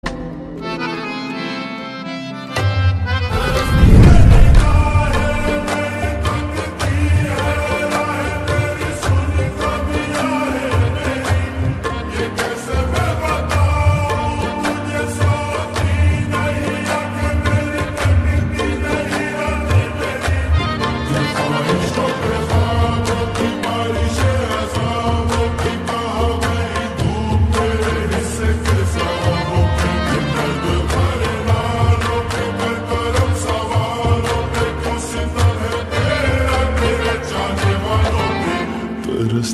Ghazal